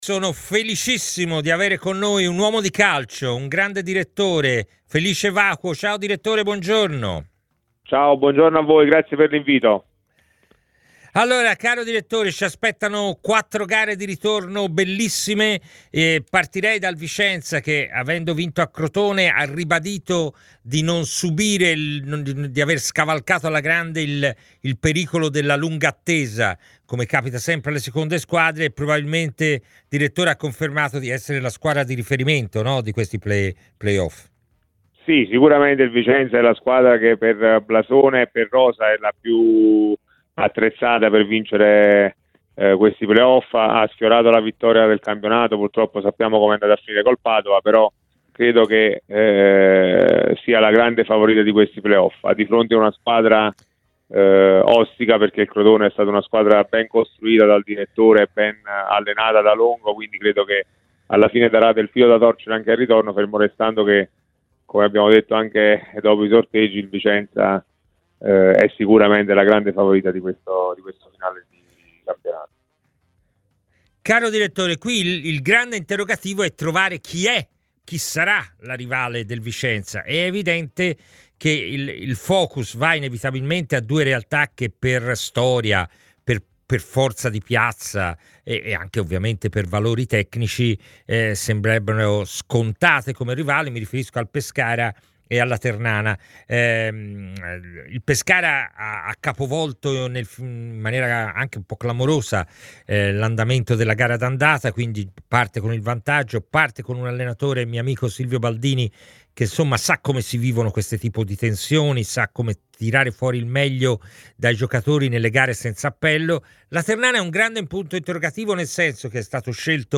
Ai microfoni di TMW Radio, nel corso di A Tutta C,